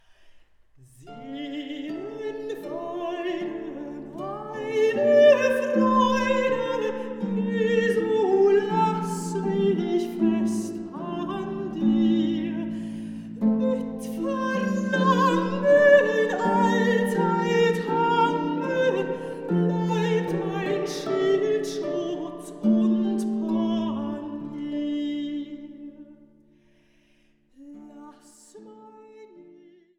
Mezzosopran
Viola da Gamba
Harfe